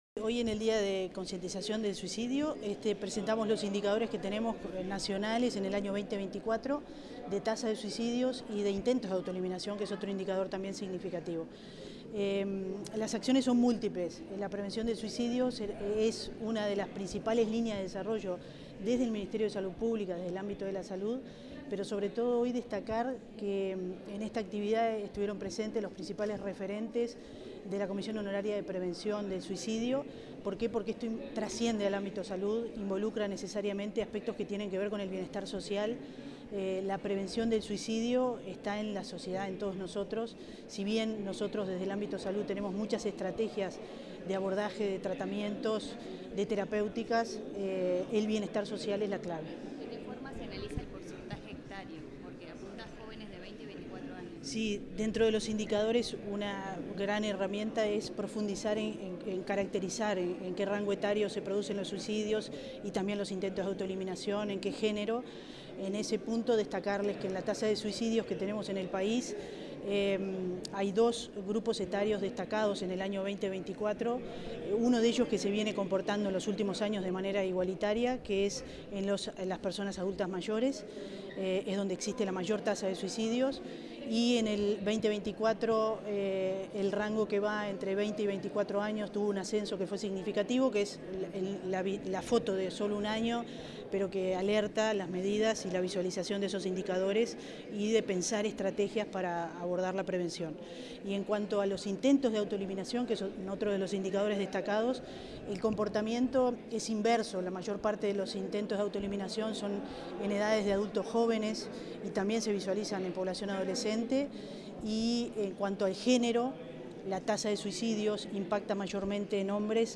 Declaraciones de la directora general de Salud, Fernanda Nozar | Presidencia Uruguay